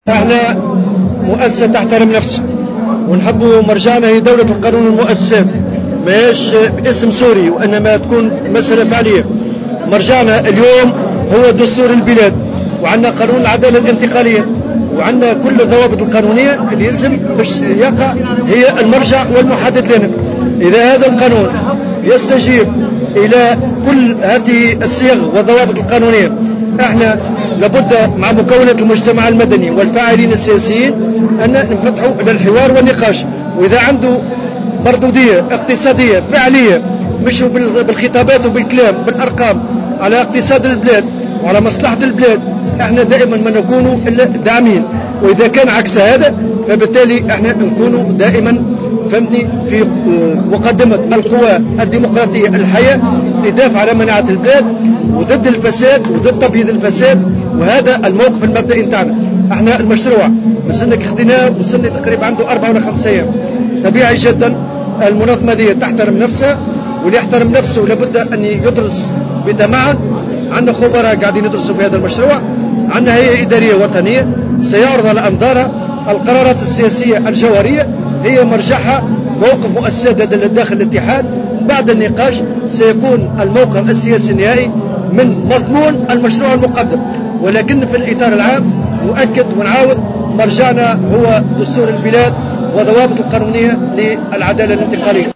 وأضاف الطبوبي في تصريح لمراسل الجوهرة اف ام خلال الاحتفال بعيد الشغل ببطحاء محمد علي بالعاصمة، أن الاتحاد سيدعم المشروع إذا كان يستجيب للقانون والدستور وله مردودية اقتصادية فعلية بالأرقام، أما إذا كان عكس هذا فإن الاتحاد سيكون في مقدمة القوى الديمقراطية للتصدي له.